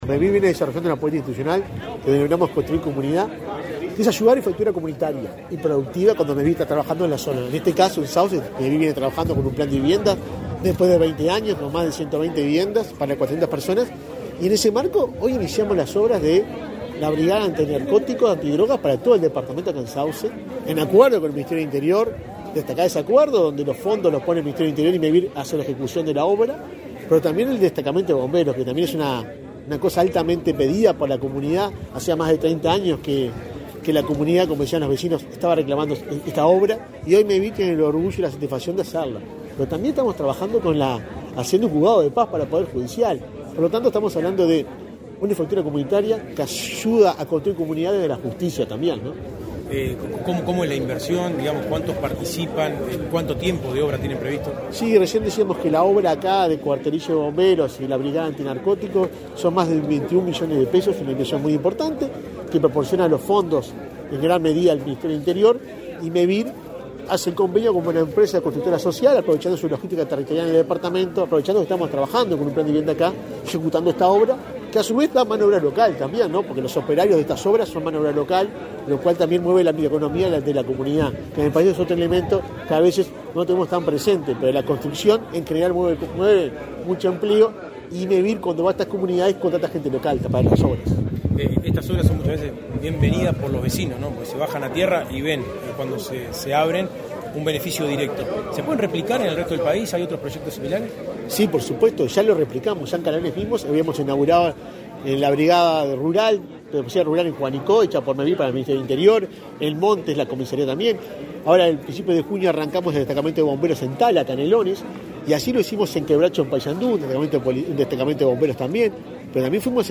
Declaraciones a la prensa del presidente de Mevir, Juan Pablo Delgado
Declaraciones a la prensa del presidente de Mevir, Juan Pablo Delgado 18/05/2023 Compartir Facebook X Copiar enlace WhatsApp LinkedIn Tras participar en el lanzamiento de obras de un cuartelillo de bomberos y una brigada antidrogas en Sauce, departamento de Canelones, este 18 de mayo, el presidente de Mevir, Juan Pablo Delgado, realizó declaraciones a la prensa.
mevir prensa.mp3